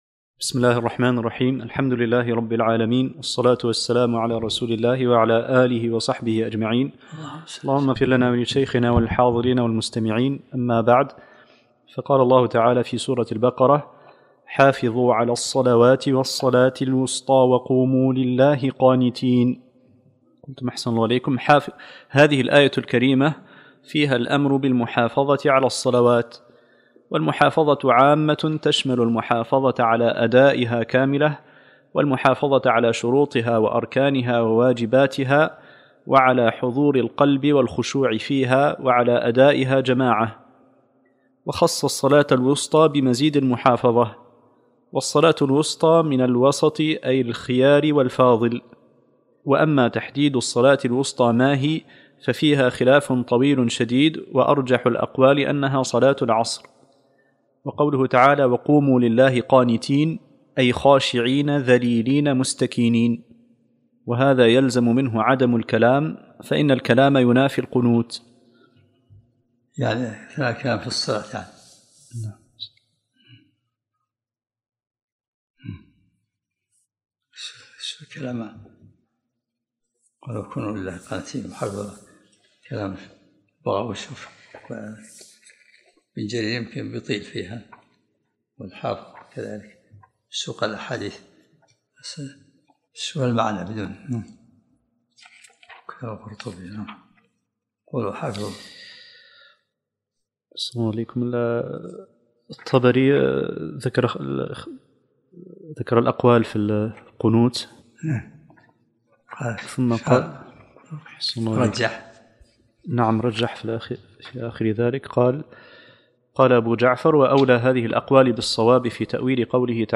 الدرس الثامن عشرمن سورة البقرة